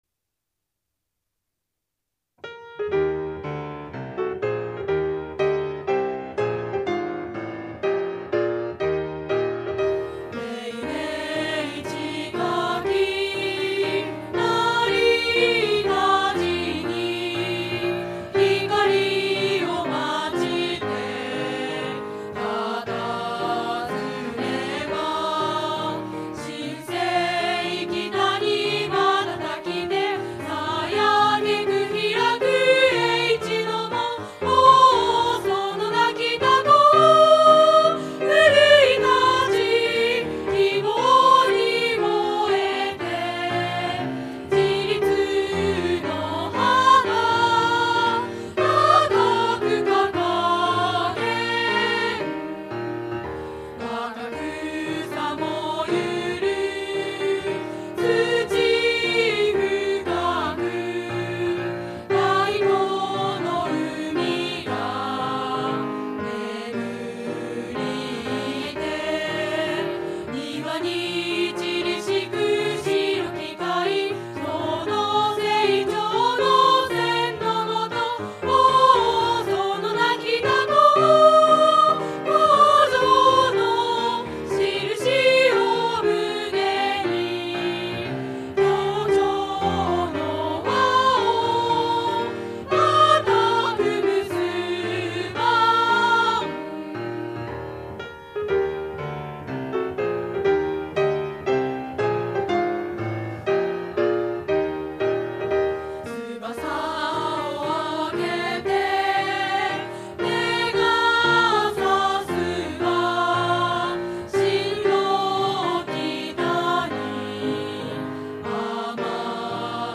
校歌(MP3_2.40MB